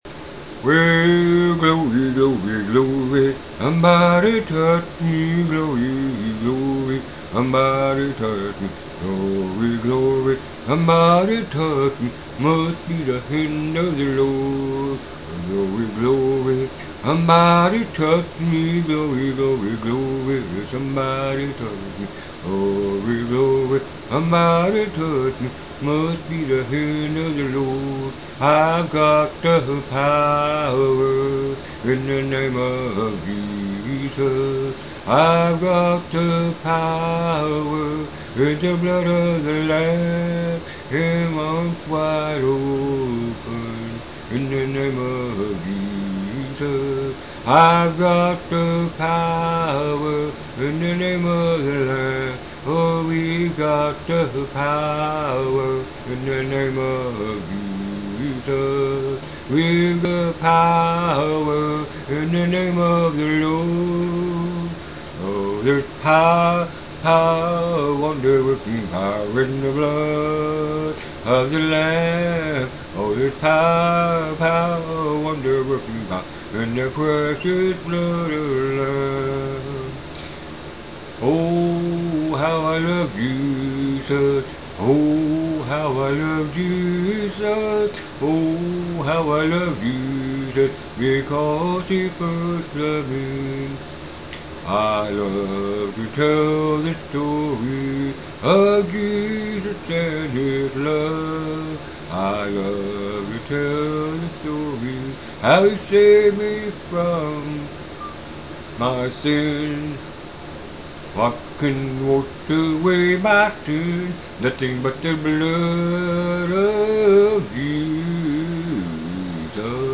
Tags: RELIGION SINGING SERMONS